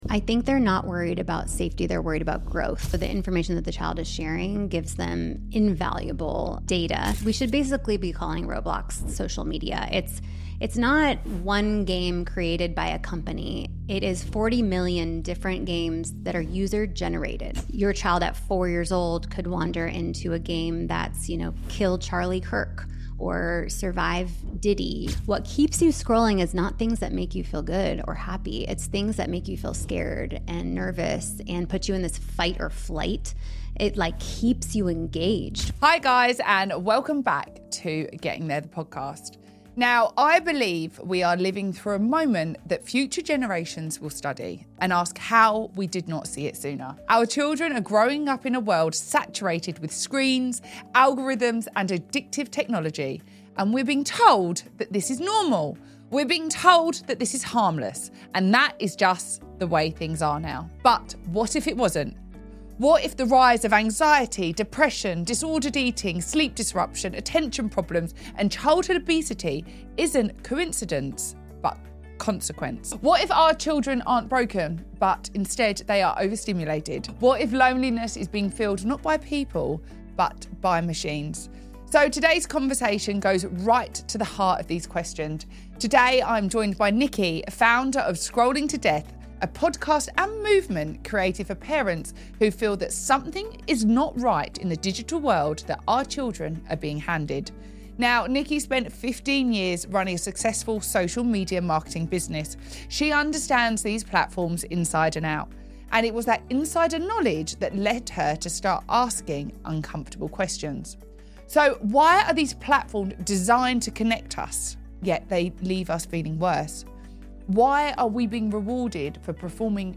This is not a fear-based conversation.